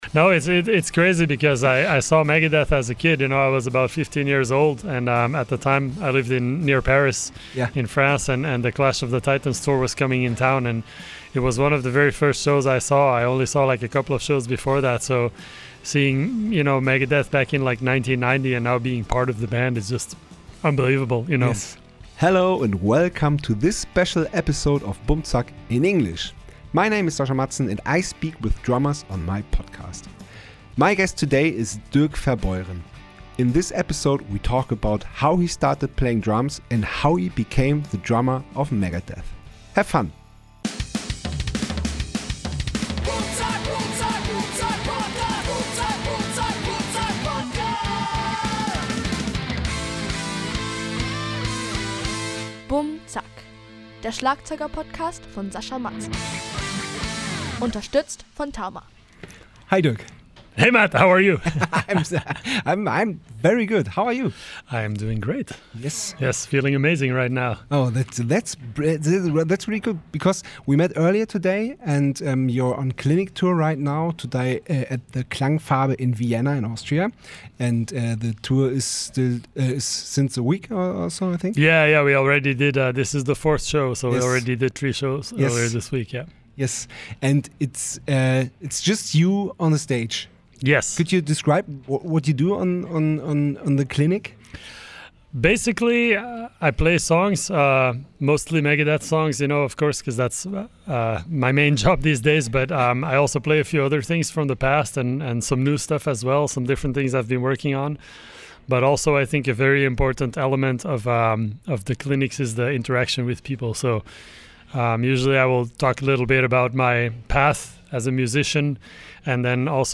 Letzte Episode 115 – Dirk Verbeuren (Megadeth) (in english) 14. April 2025 Nächste Episode download Beschreibung Teilen Abonnieren My guest today is Dirk Verbeuren. In this Episode we talk about how he started playing drums and how he became the drummer of Megadeth.